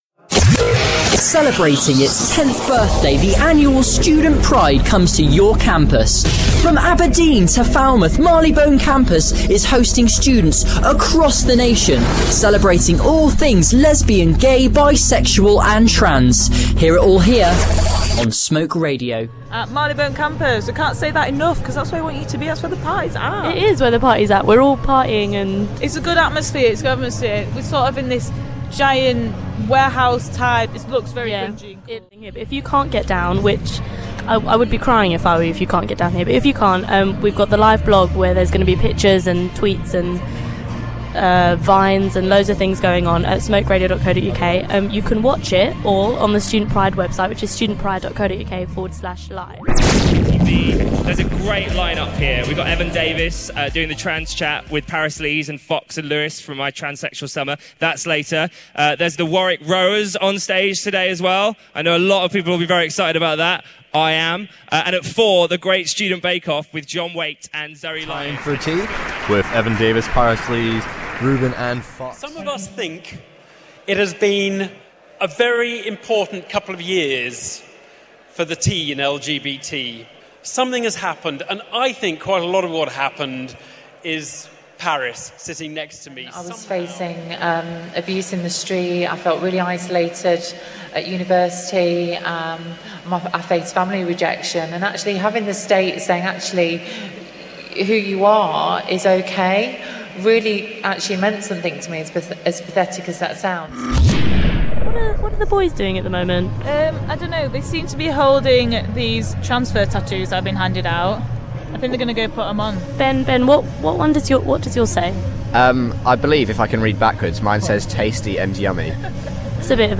Montage from beginning of Student Pride best bits podcast being promoted right now!